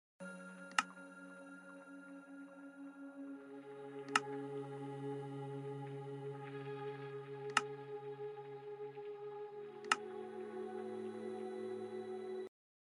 حركات اللاعب في ماين كرافت sound effects free download